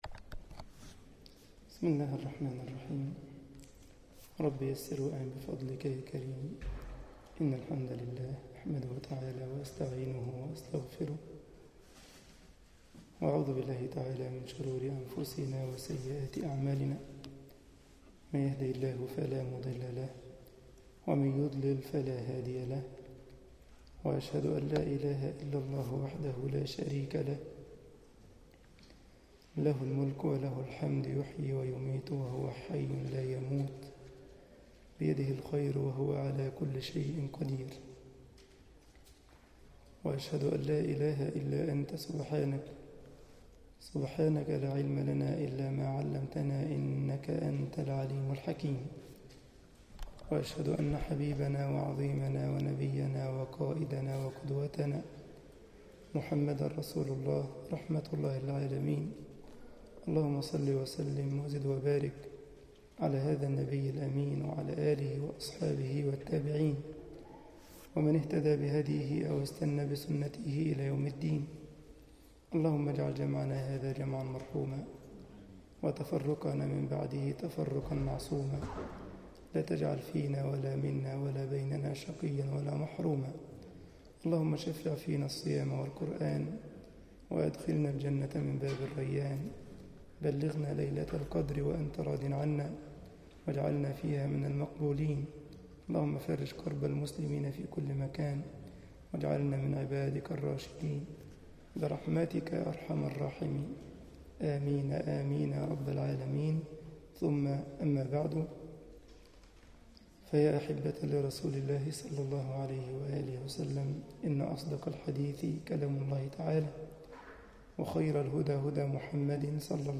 مسجد الجمعية الإسلامية بالسارلند ـ ألمانيا درس 10 رمضان 1433هـ